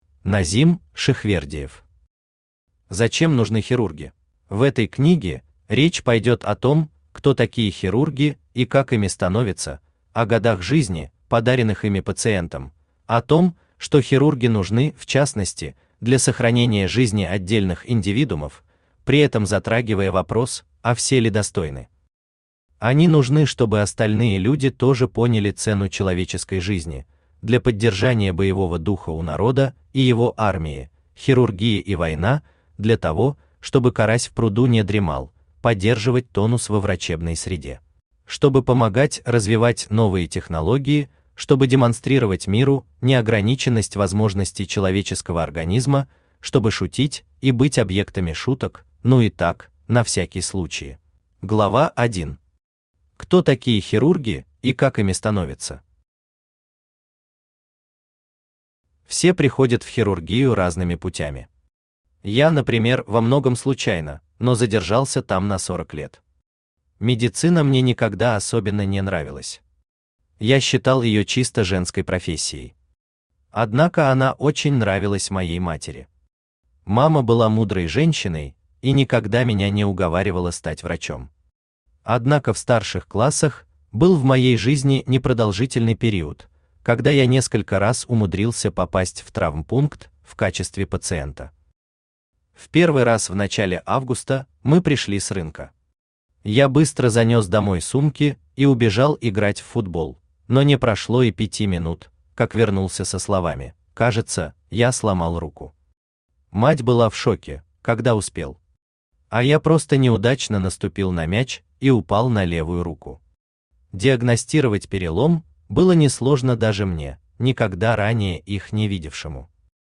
Аудиокнига Зачем нужны хирурги?
Автор Назим Низамович Шихвердиев Читает аудиокнигу Авточтец ЛитРес.